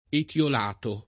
etLol#to] agg.